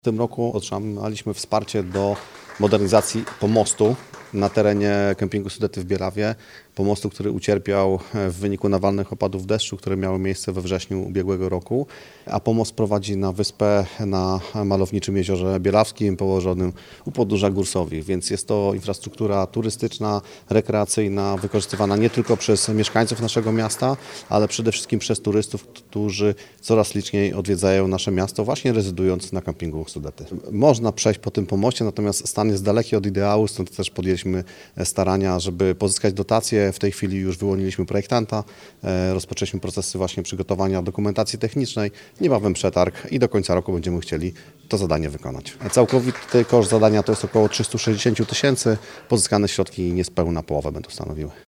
Burmistrz Bielawy Andrzej Hordyj poinformował, że fundusze zostaną przeznaczone na modernizację pomostu na kempingu Sudety, zniszczonego podczas nawalnych opadów, które wywołały powódź w 2024 roku.